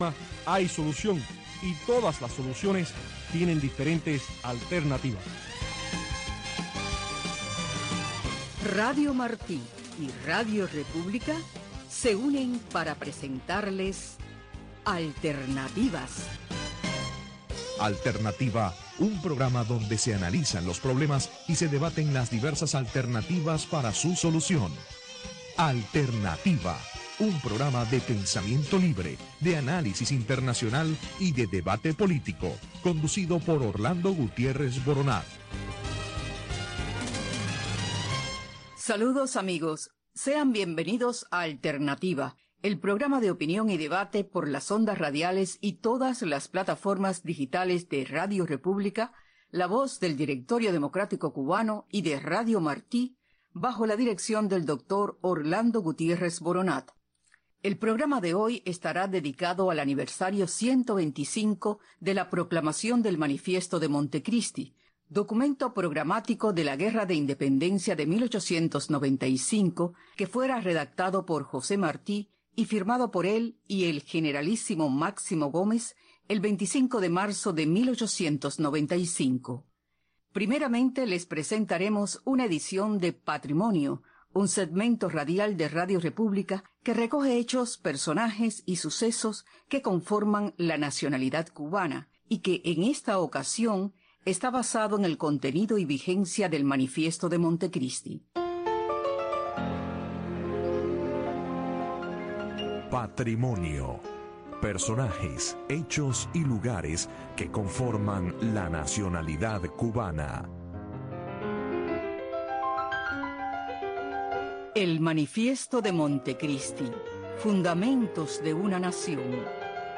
El programa radial Alternativa es un programa de panel de expertos